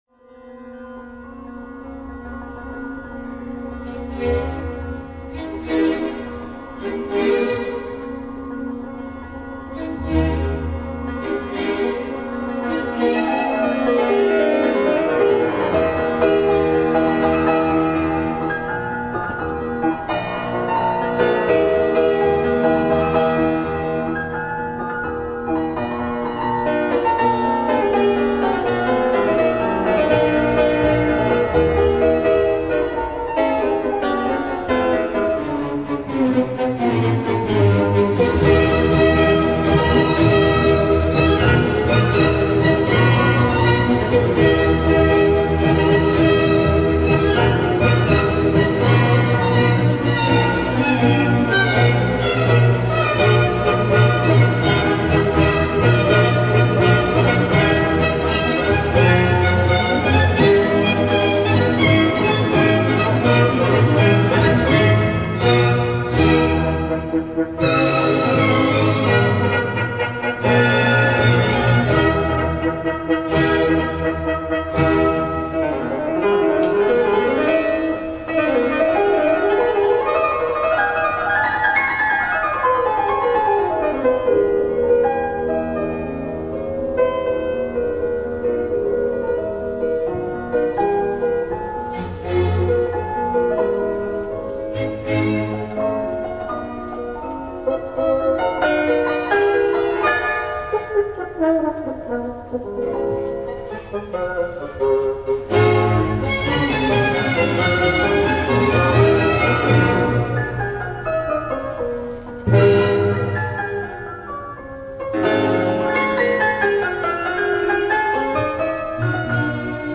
in der Bad Hersfelder Stiftsruine sitze
das zweieinhalb Meter hohe Stativ mit dem bescheidenen Eigenbau-Mikrofonsystem neben mir